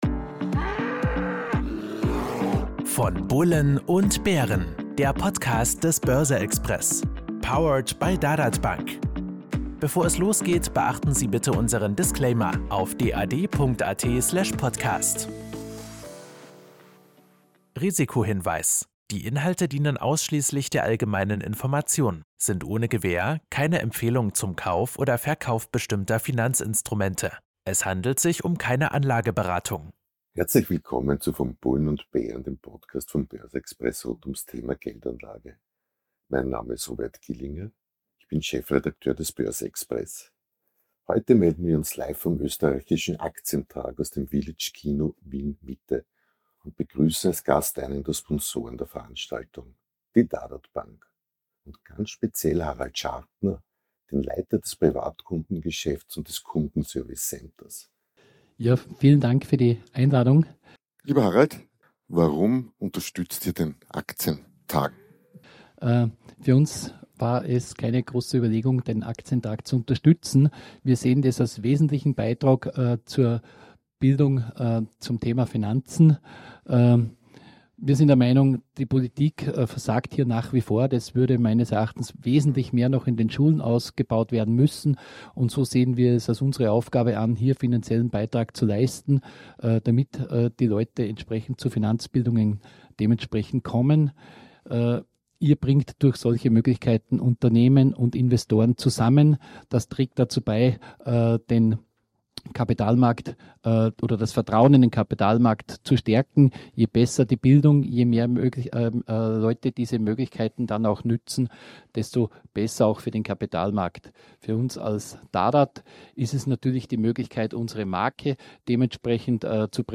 In Folge 71 des Podcasts „Von Bullen und Bären“ waren wir live am 1. Österreichischen Aktientag im Village Center Wien Mitte.
Normalerweise in kühler Tonstudio-Umgebung, diesmal vor großer Leinwand im großen Kino - beim Österreichischen Aktientag von Börse Express und Börse Social Network.